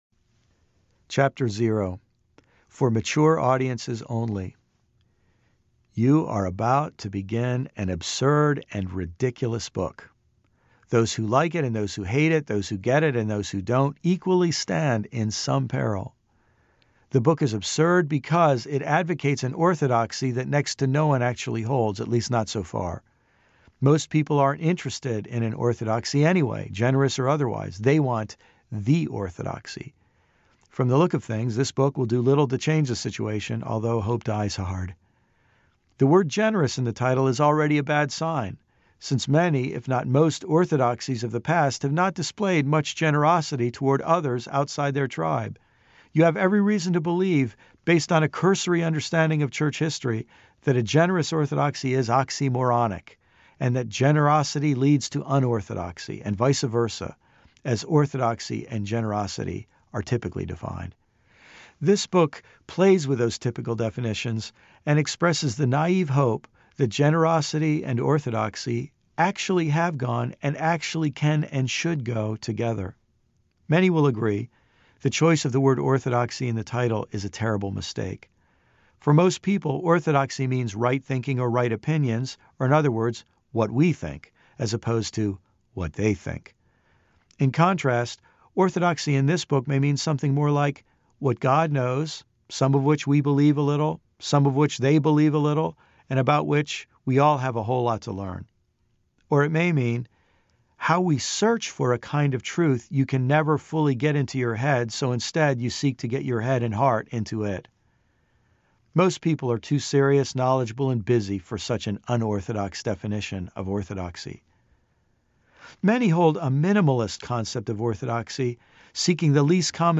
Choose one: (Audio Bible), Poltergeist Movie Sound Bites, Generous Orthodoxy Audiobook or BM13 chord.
Generous Orthodoxy Audiobook